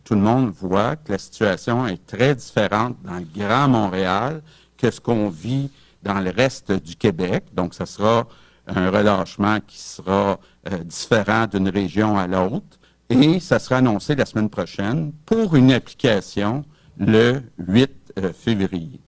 Le premier ministre a fait cette précision en point de presse mardi après-midi.